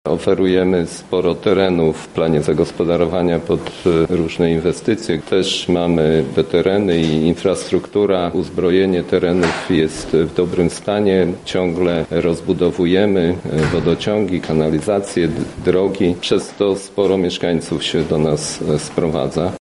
Staramy się wykorzystywać nasze bezpośrednie położenie przy Lublinie i Świdniku- mówi wójt gminy Głusk Jacek Anasiewicz